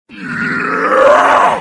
moarg_alert_04.mp3